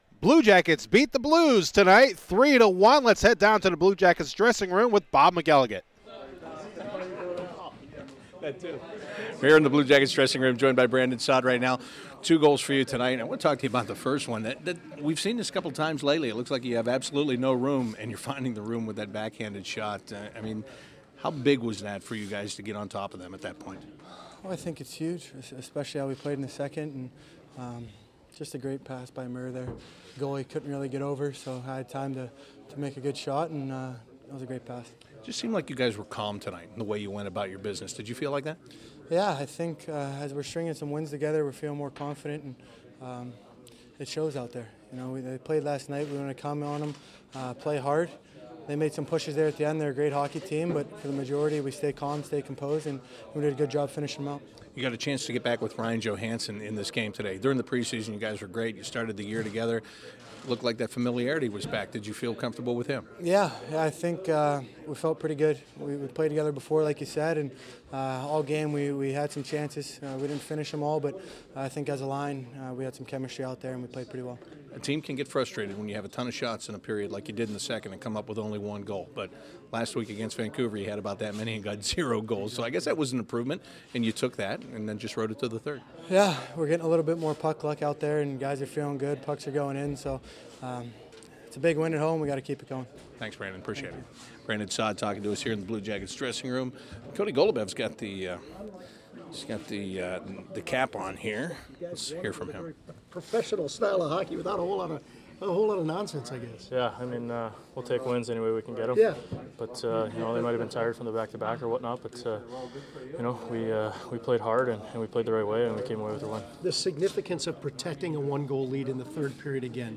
Brandon Saad, Cody Goloubef, Kerby Rychel and Jared Boll talk in the locker room after getting their 3rd straight victory inside Nationwide Arena Tuesday night against the St. Louis Blues. The players talk about the team becoming more confident as they gather some more wins.